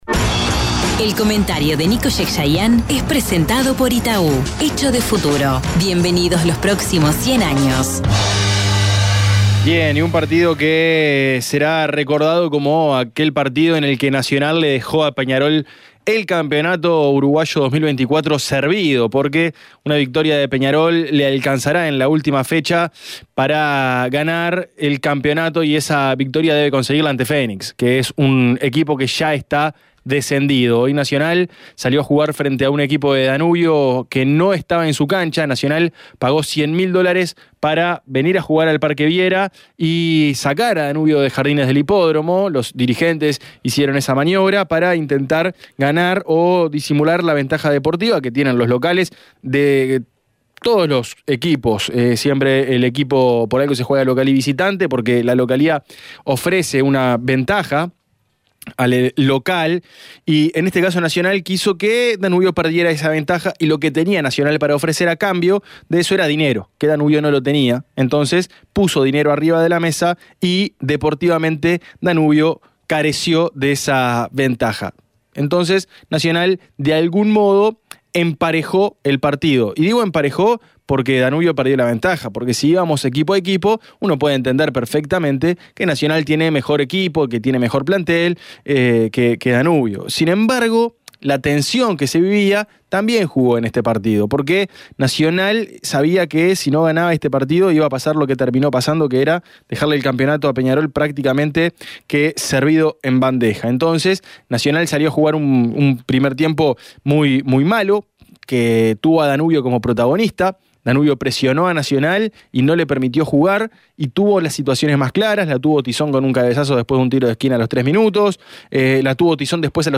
Periodístico deportivo